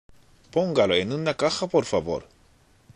（ポンガロ　エンヌナカハ　ポルファボール）